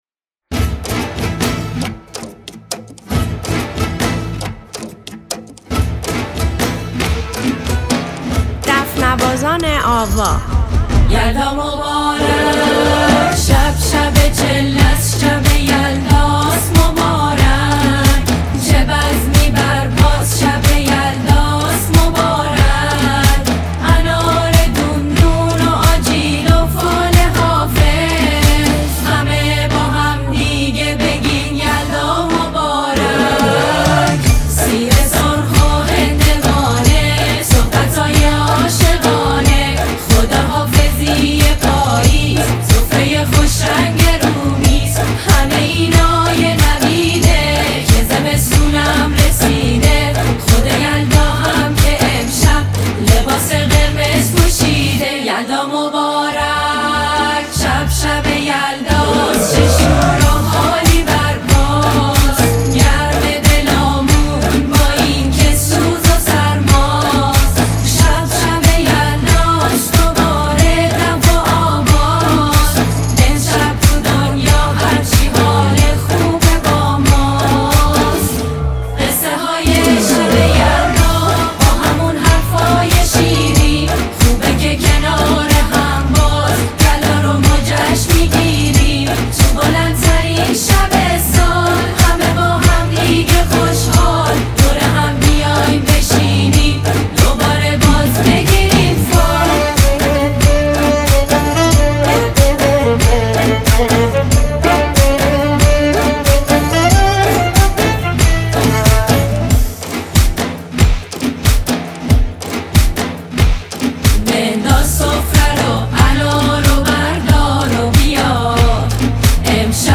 با نوای دلنشین دف و ریتم‌های شاد و اصیل ایرانی.
دف نوازیشب یلداجشن شب یلداموزیک شب یلدا